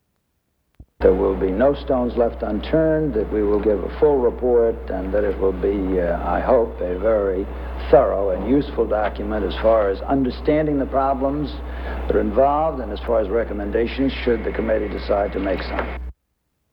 U.S. Vice President Nelson Rockefeller speaks about his commission investigating the CIA and the FBI